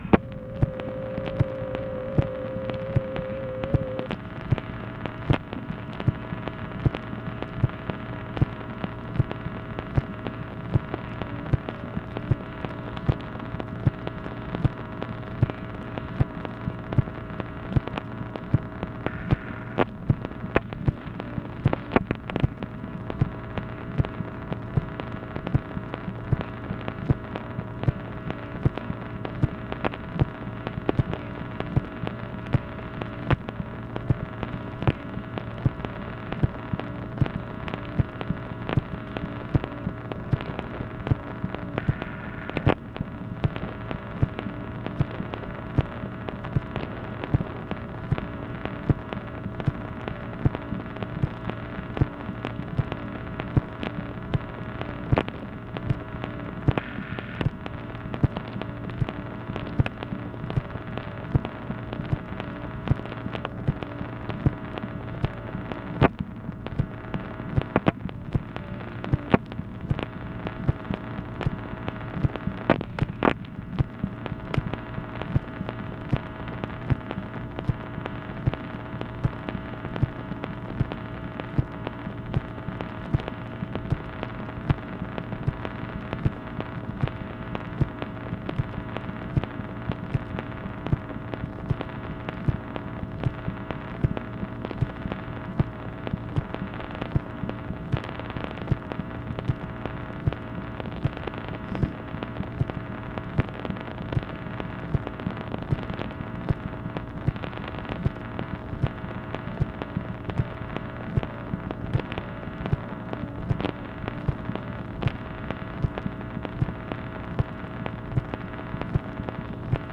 MACHINE NOISE, May 31, 1965
Secret White House Tapes | Lyndon B. Johnson Presidency